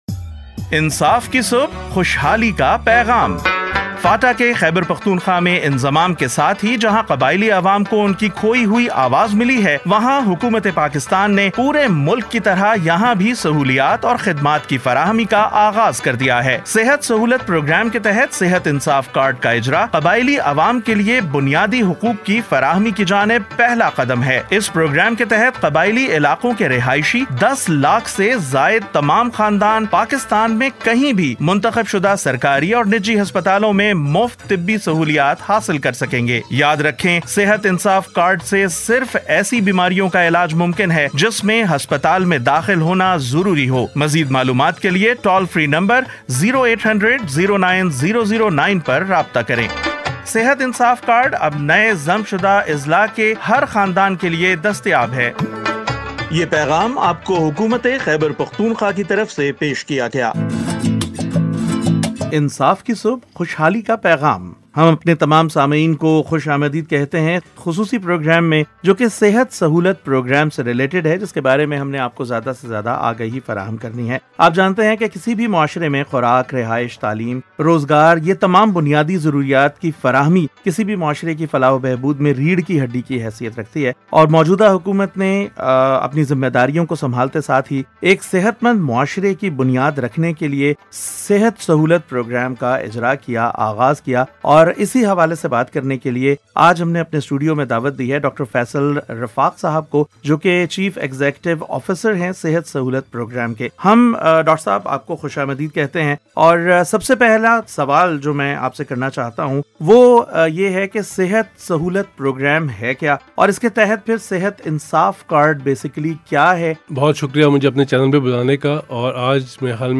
Sehat Insaf Card – Radio Program – Communications Research Strategies (CRS)